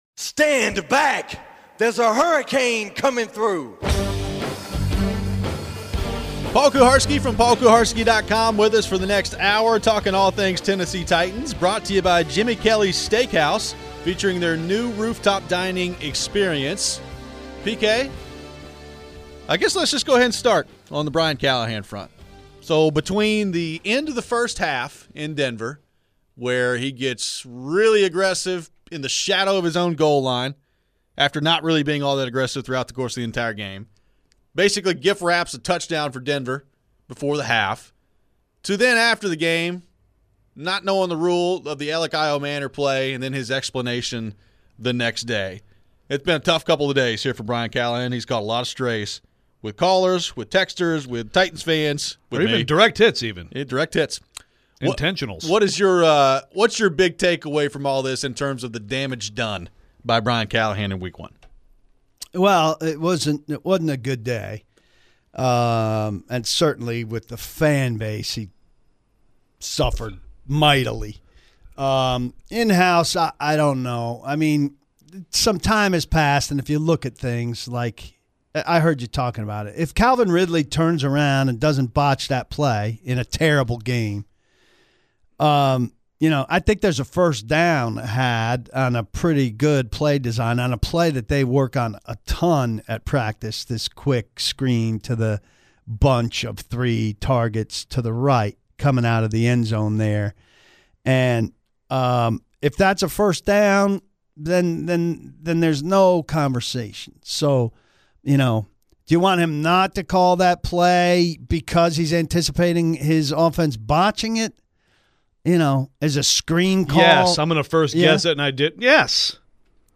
L'Jarius Sneed was on a pitch count, what does that mean for this week? We close out the show with your phones.